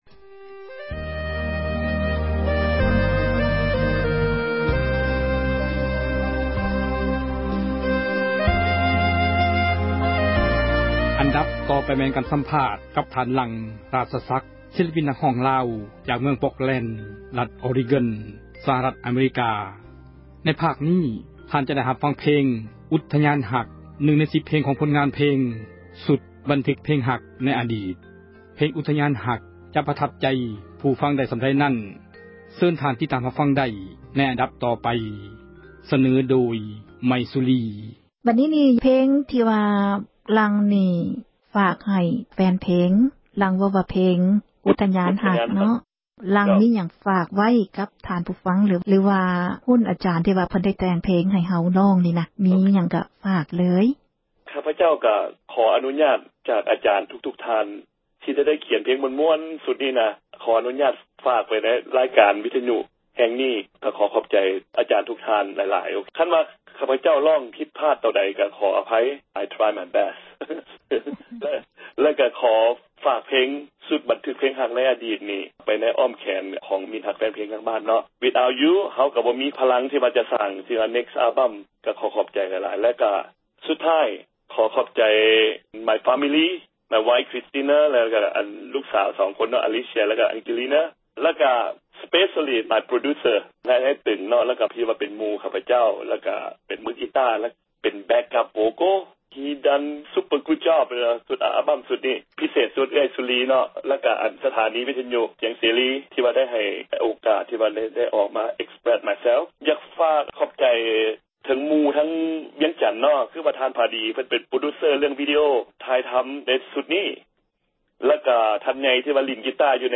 ສັມພາດສິລປິນ ນັກຮ້ອງລາວ ພາກ 2